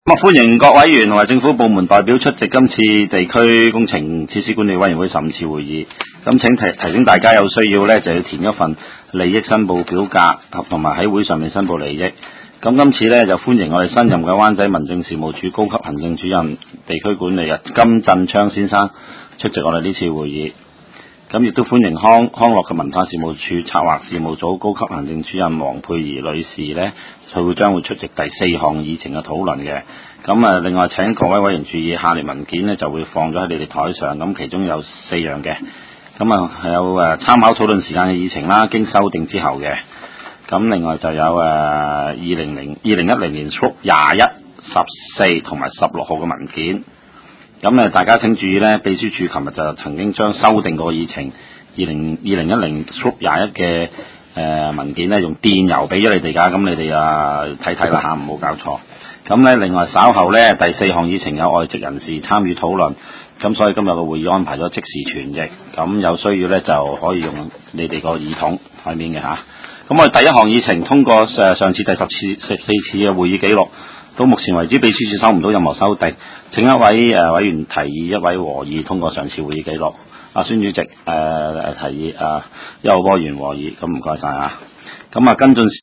地區工程及設施管理委員會第十五次會議
灣仔民政事務處區議會會議室